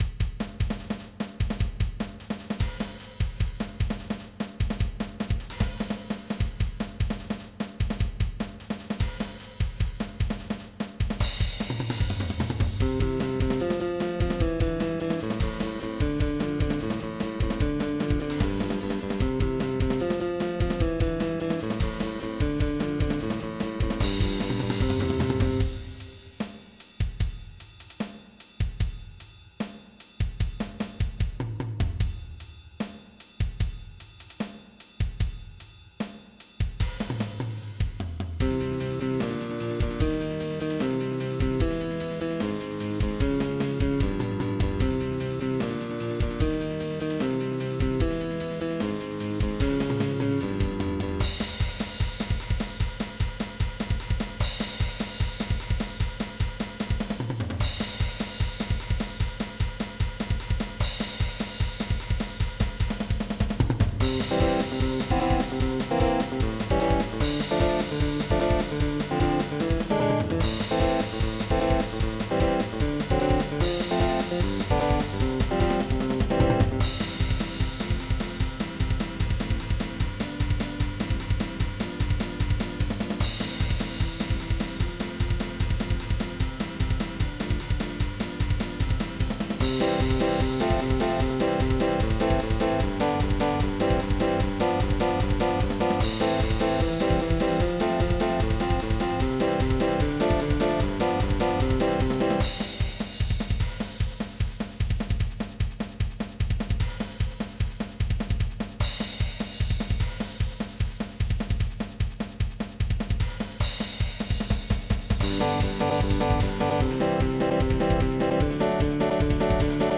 8ビートシンプル1　前向き　アルペ8上　最低ふてん　8ビート
8ビートシンプル4　無調風　花風最低ふてん　バラード
8ビート変化ある　ラブラブラブ　ロック1　低音ふてん
8ビートシンコペ　試作1　ロック2　低音和音交互8
行進曲5　試作10　軽快2　きゅう8和音8刻み　ジャズ1